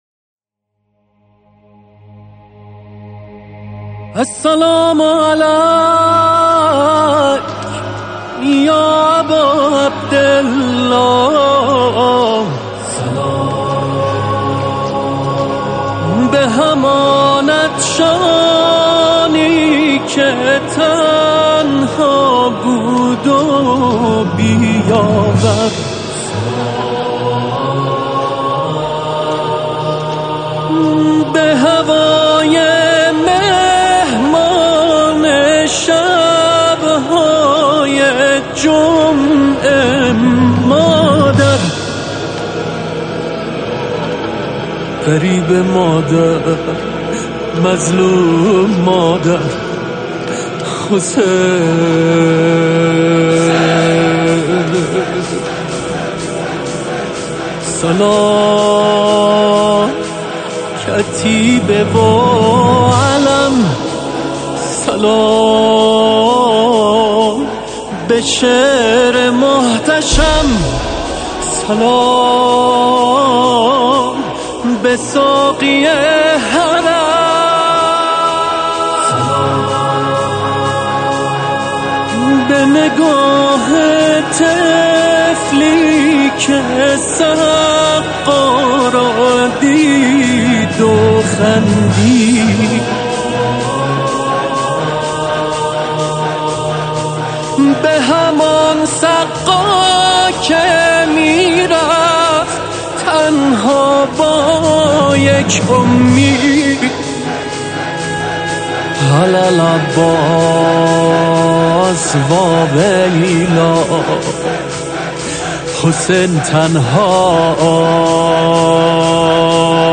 نوحه زیبای